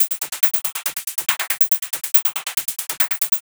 tx_perc_140_swifflehats.wav